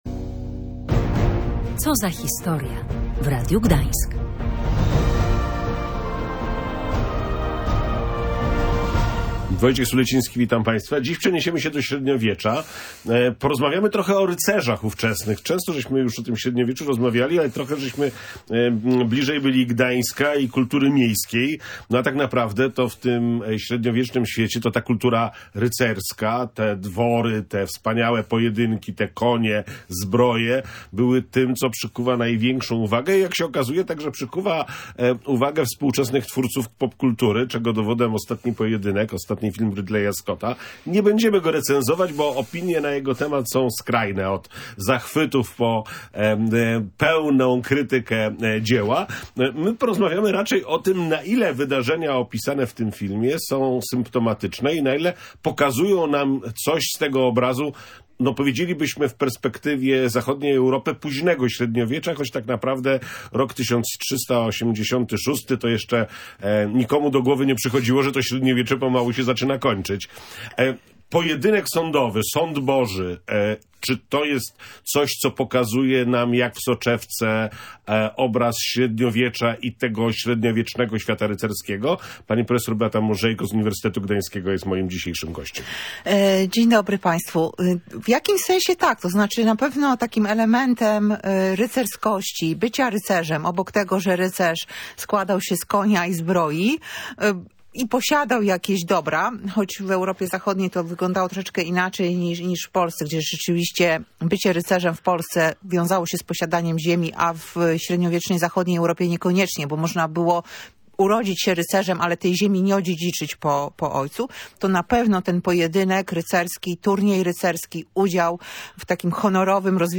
Świat bohaterów w srebrnych zbrojach. Rozmowa o kulturze rycerskiej i życiu na średniowiecznych zamkach